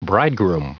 Prononciation du mot bridegroom en anglais (fichier audio)
Prononciation du mot : bridegroom